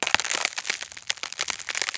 freeze.wav